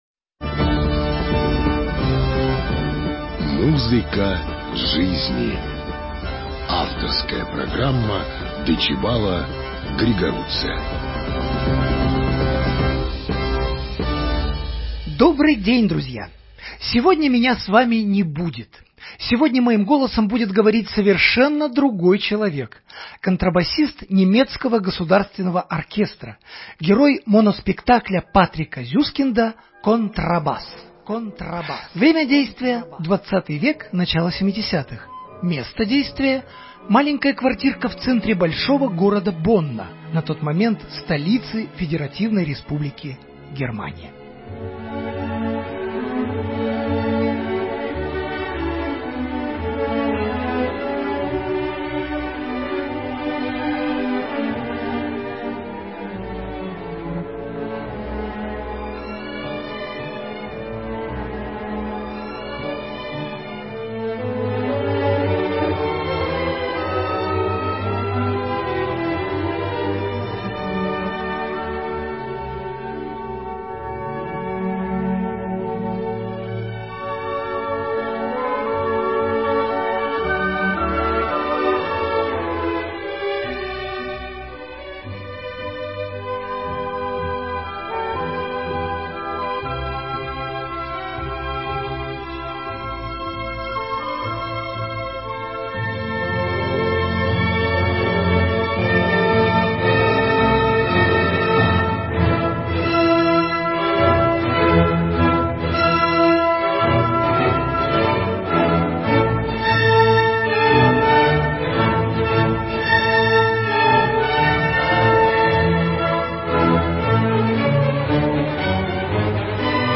Безусловно, украшением повествования является музыка, исполненная на контрабасе.